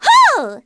Ophelia-Vox_Casting4_kr.wav